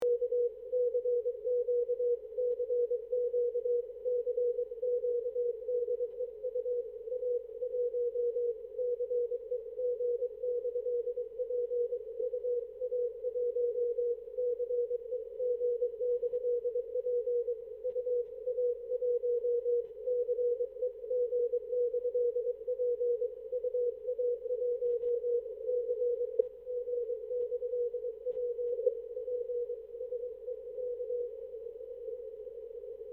Во время QSO забыл нажать запись, потом записал CQ. Принимал Вас на ант. INV 10-15-20.